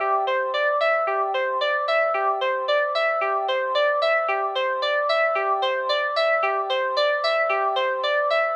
C - SynthRiff_Syrup01.wav